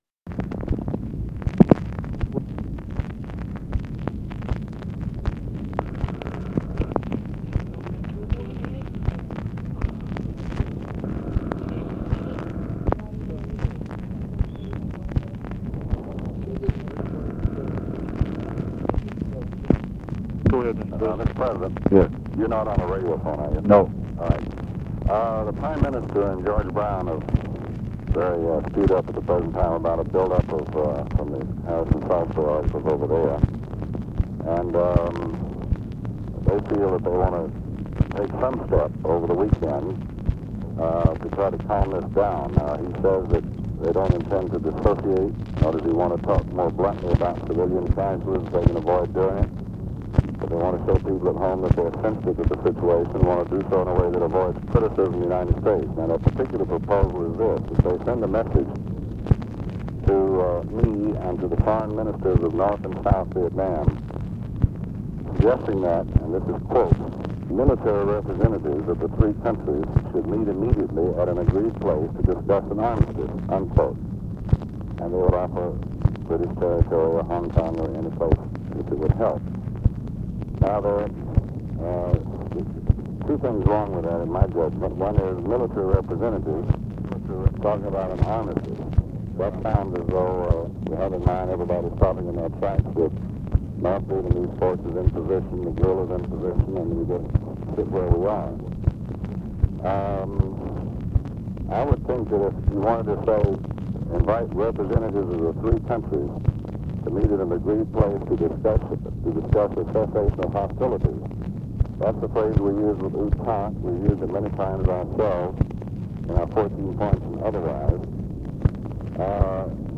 Conversation with DEAN RUSK, December 30, 1966
Secret White House Tapes